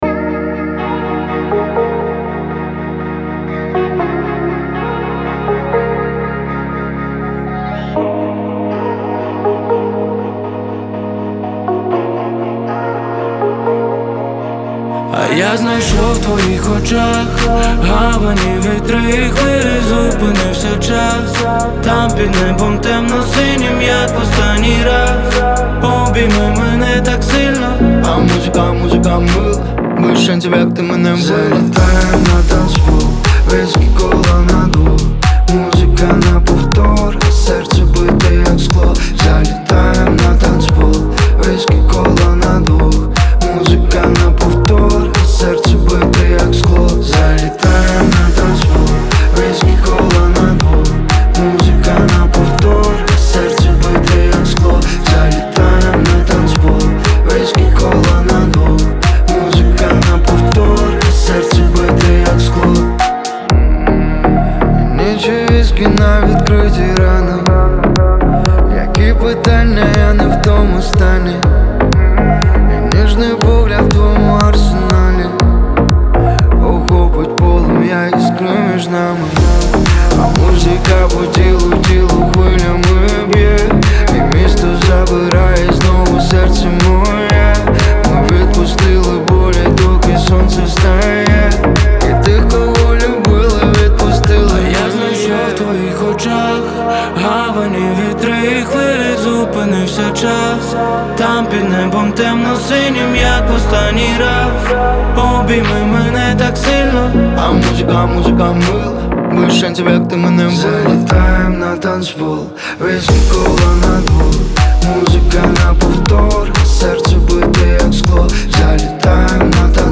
• Жанр: Pop, Rap